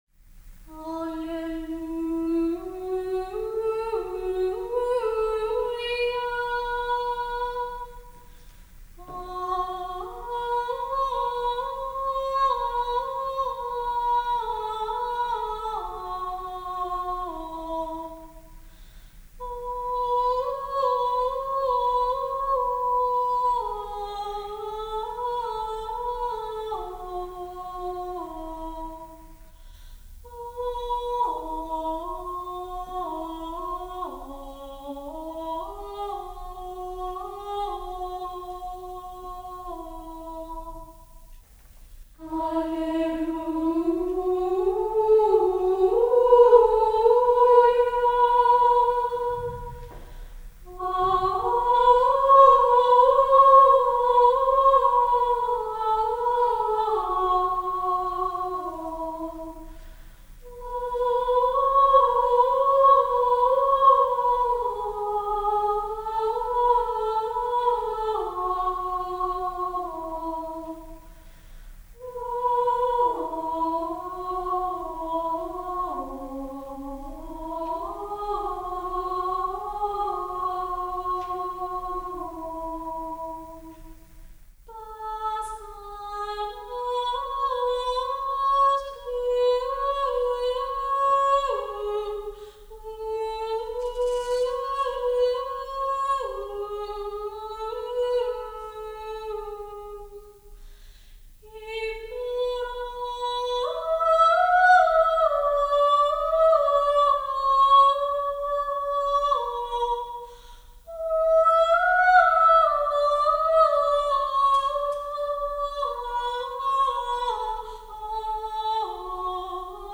ƒOƒŒƒSƒŠƒA¹‰ÌE‘IW